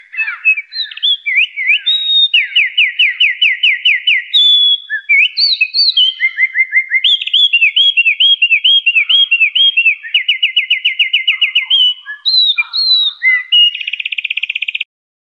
鹪鹩鸟叫声